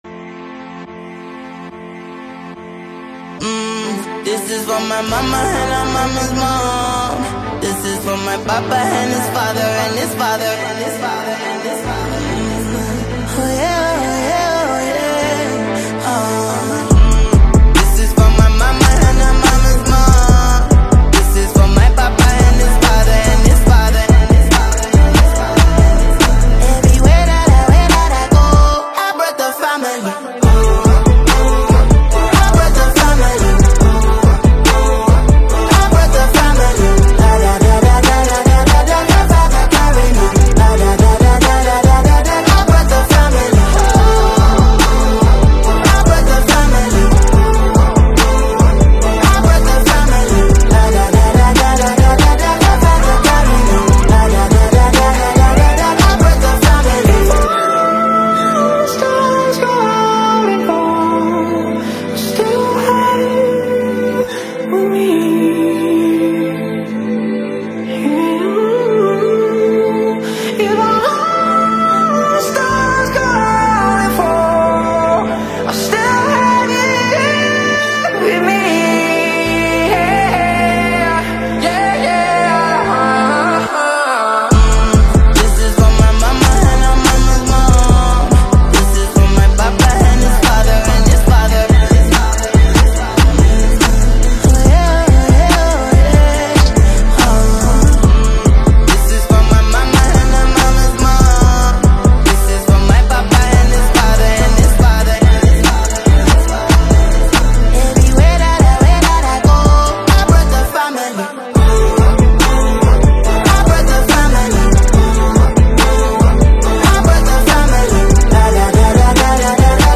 sultry afrobeat vibes
With its catchy hooks, infectious beats
impressive vocal range